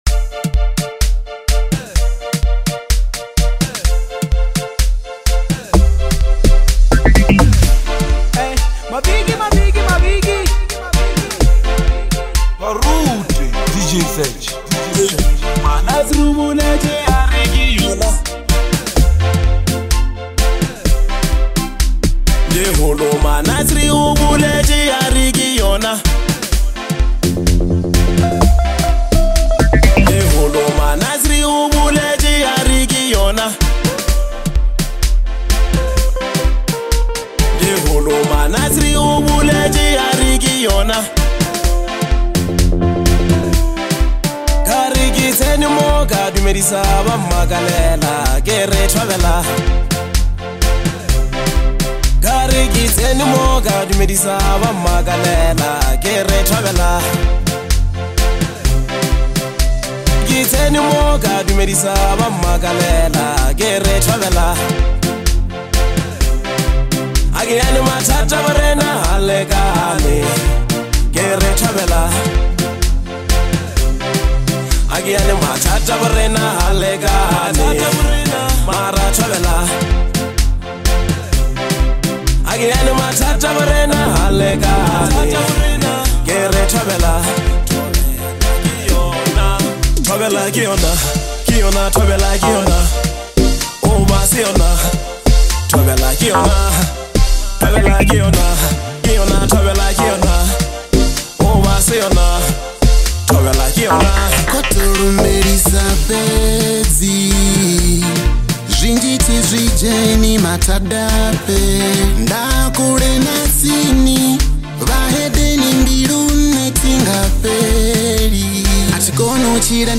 a vibrant and energetic track